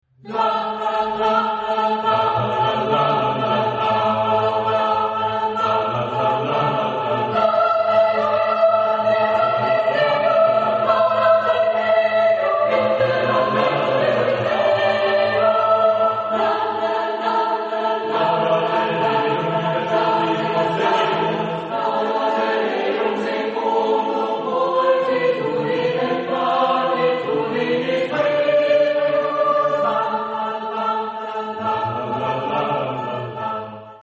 Genre-Style-Form: Psalm ; Sacred
Mood of the piece: fast ; rhythmic ; fervent
Type of Choir: SATB  (4 mixed voices )
Tonality: A aeolian